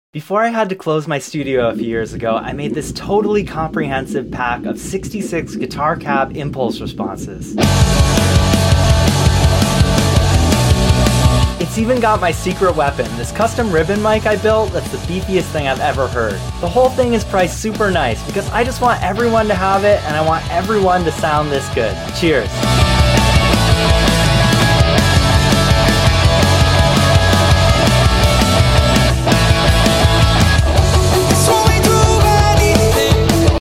Robot Dog Cabs Electric Guitar sound effects free download